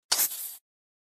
Звуки крипера
На этой странице собраны звуки крипера из Minecraft — от характерного шипения до взрыва.